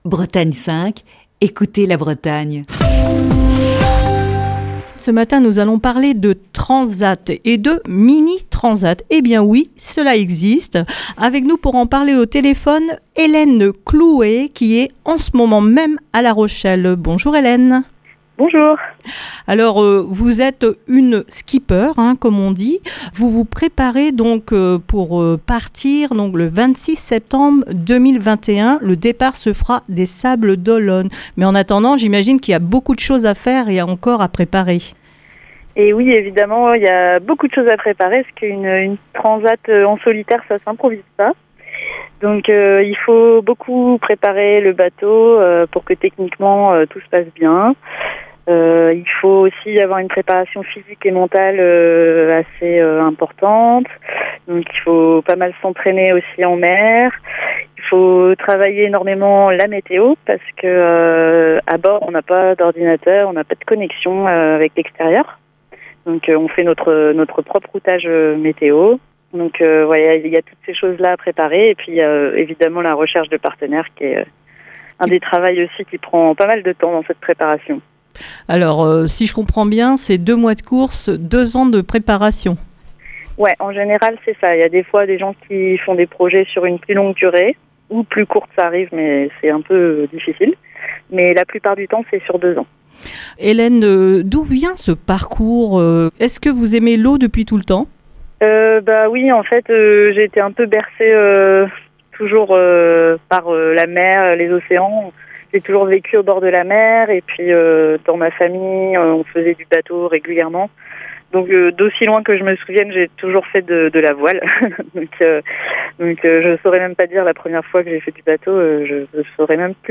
Émission du 4 février 2021.